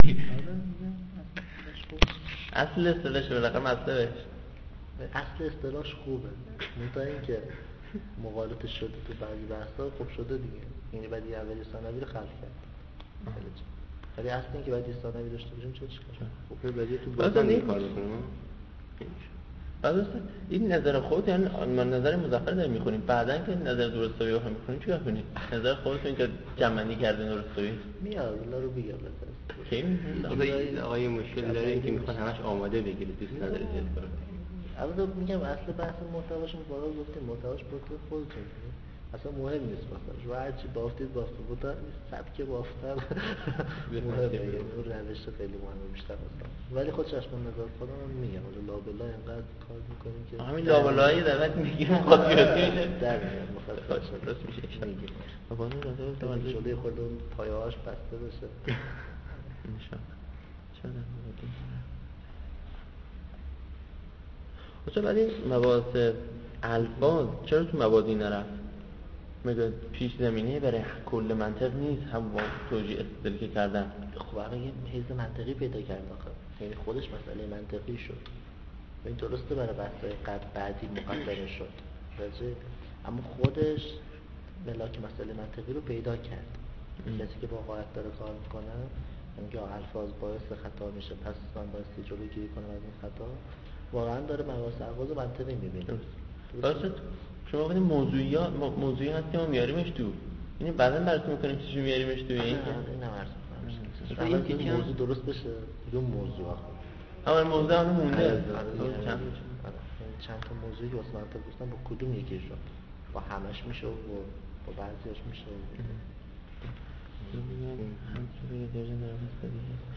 صوت دروس حوزوی- صُدا